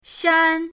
shan1.mp3